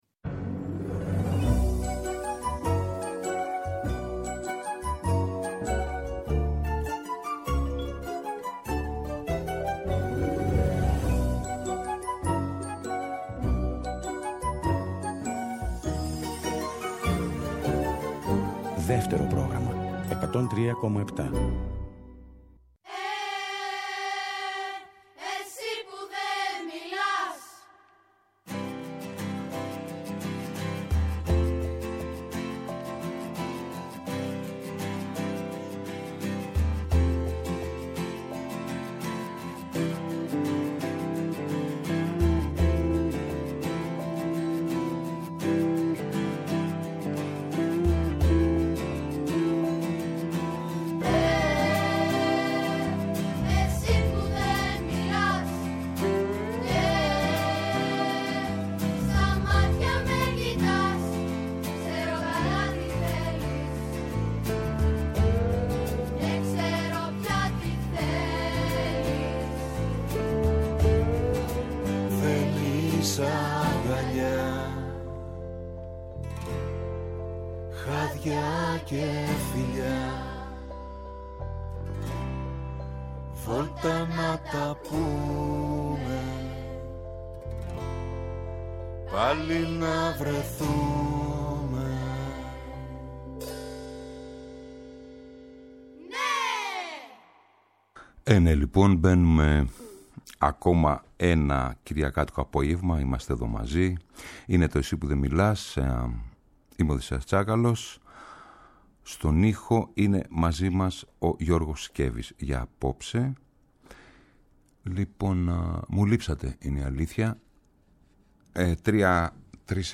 παρουσιάζει καινούργια τραγούδια από την νέα Ελληνόφωνη σκηνή που δηλώνει δυναμικά παρούσα τα τελευταία χρόνια.